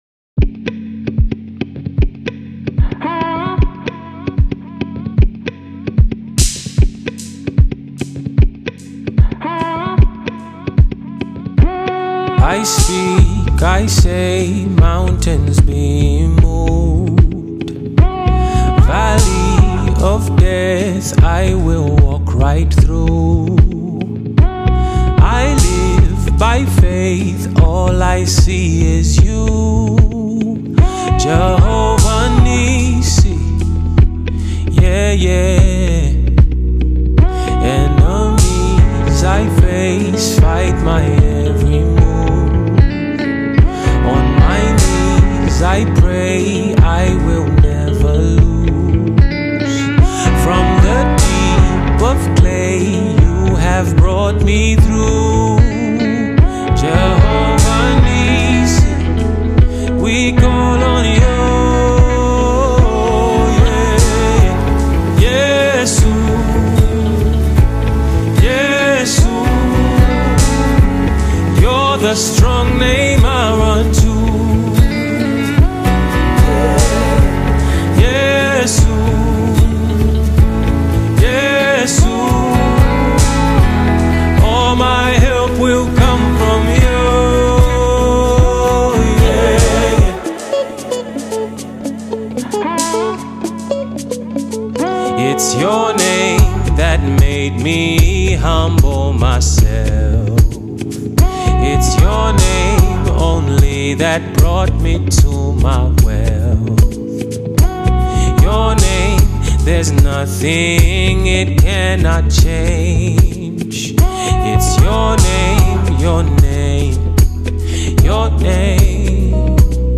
Here is a beautiful zambian gospel song